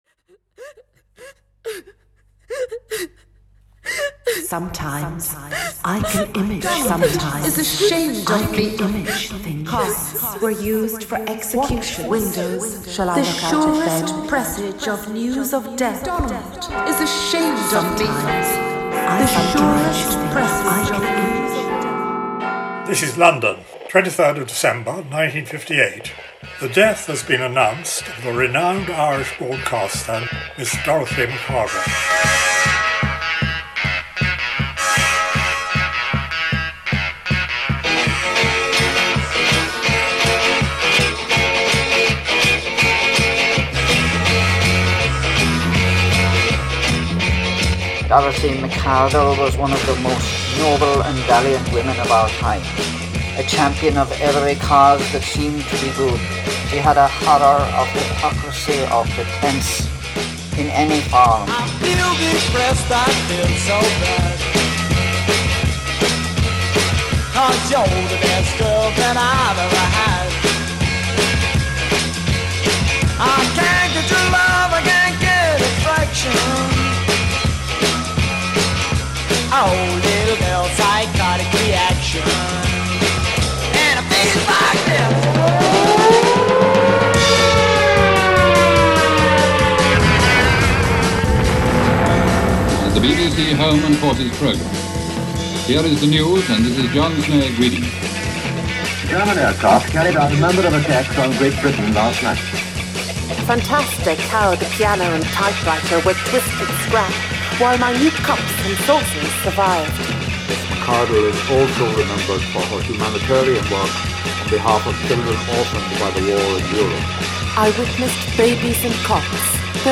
Soundscapes